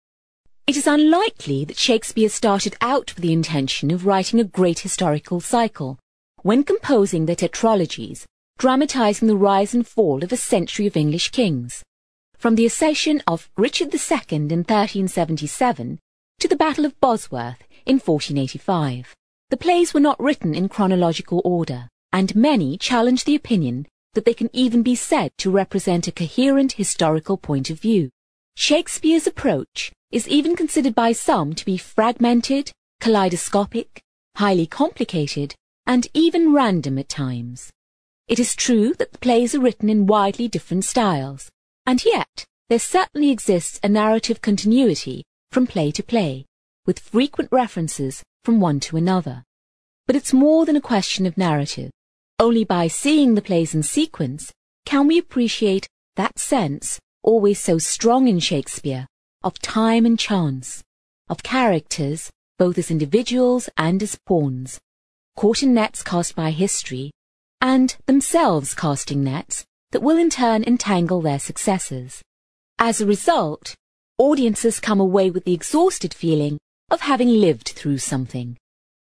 ACTIVITY 91: You are going to hear a part of a review about a staging of Shakespeare's history plays.